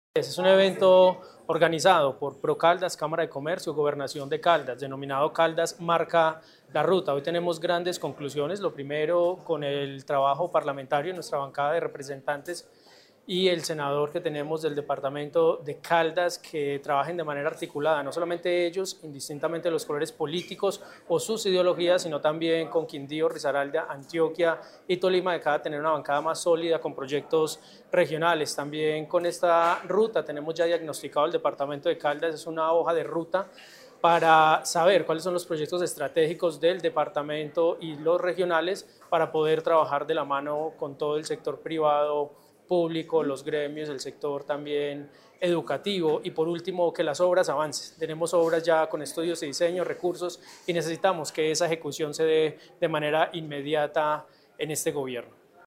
Con un mensaje de articulación y trabajo conjunto, la Secretaría de Planeación de la Gobernación de Caldas lideró el encuentro estratégico ‘Caldas Marca la Ruta: una visión de territorio para los próximos años’, un espacio que reunió a la institucionalidad, el sector privado, la academia y la bancada parlamentaria electa para construir una agenda común que proyecte al departamento en el escenario nacional.
Ronald Fabian Bonilla, Gobernador (e) de Caldas.